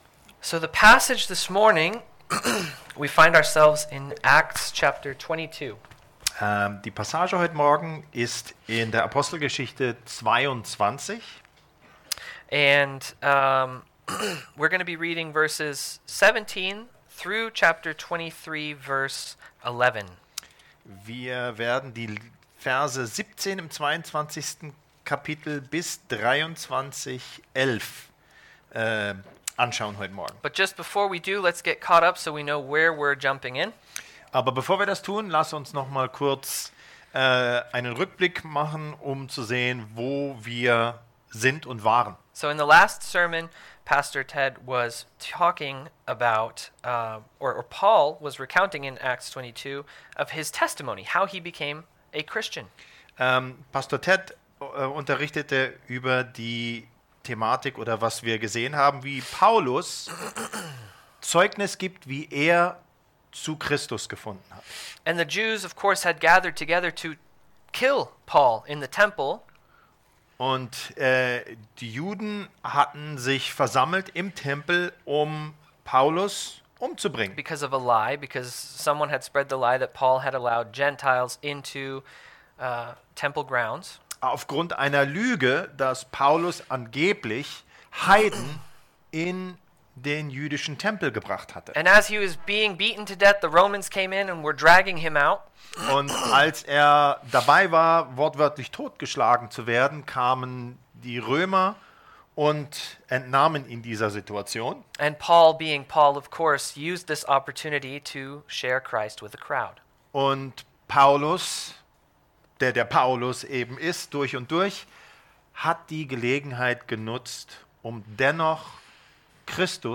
Passage: Apostelgeschichte / Acts 22:17-23:11 Dienstart: Sonntag Themen